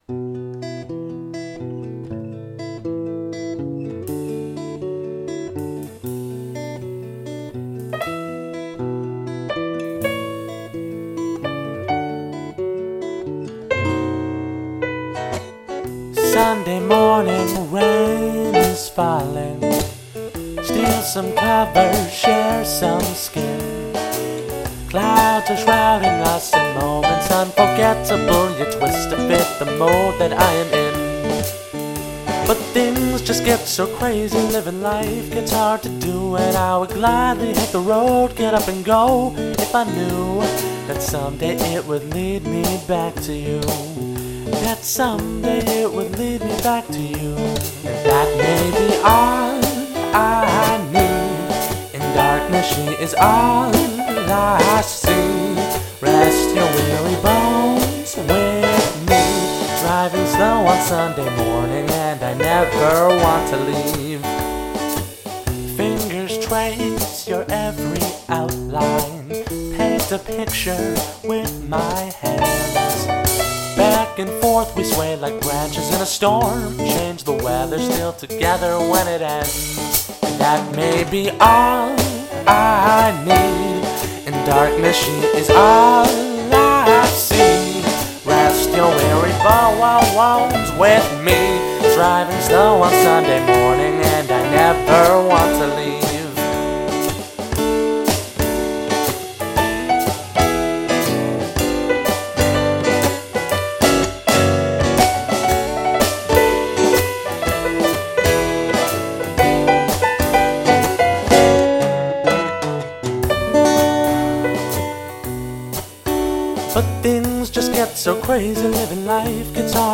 Sorry if the mix is rough, I'm new to all this.